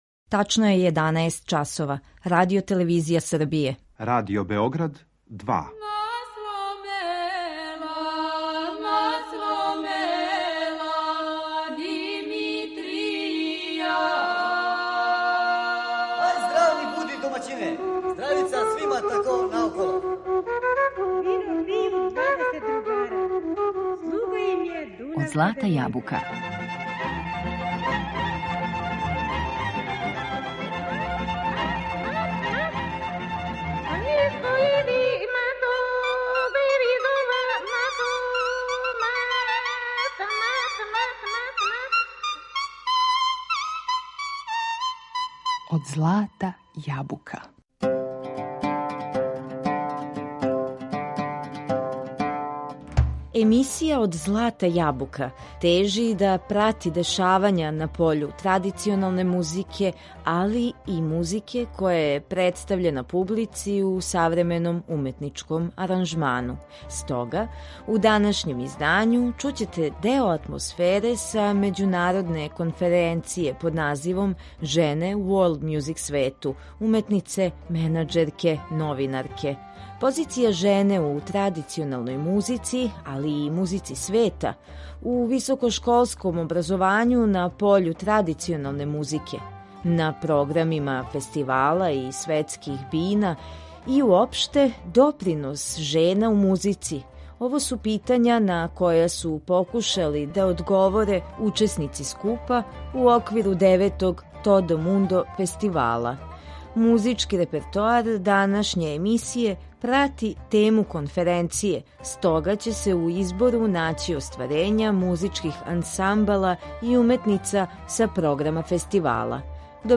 Muzički repertoar današnje emisije prati temu konferencije, stoga će se u izboru naći ostvarenja muzičkih ansambala i umetnica sa programa festivala „Todo mundo".